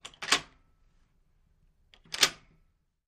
Door Latches Locked, 2 Latches Turned & Locked.